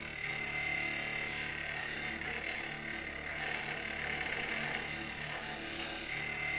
cutter.ogg